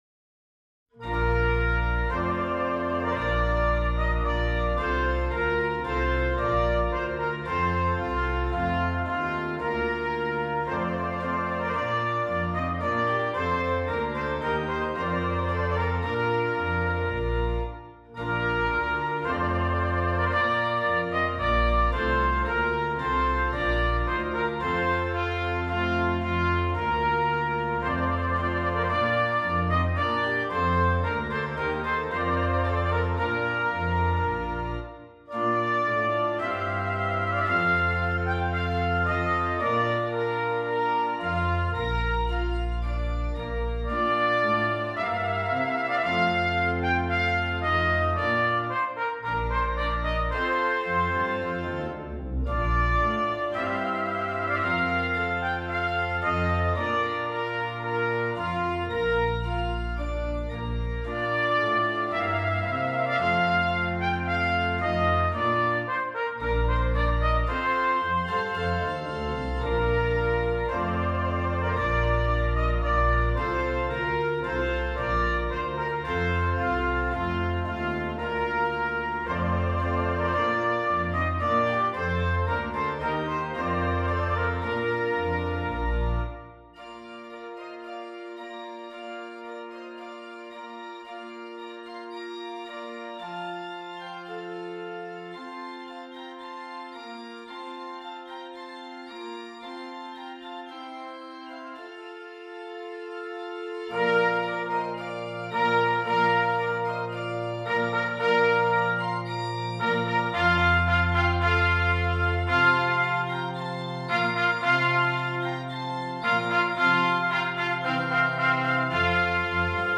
Brass
2 Trumpets and Keyboard